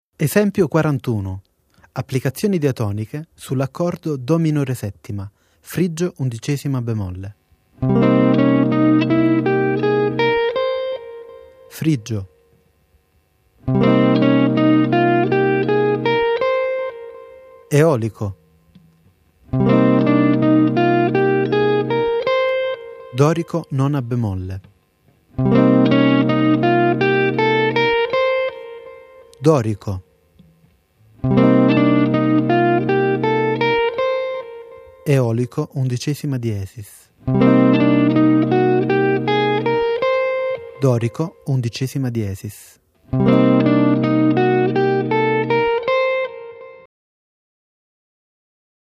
Soluzioni modali su Cmin7
Nome del modo: Frigio b11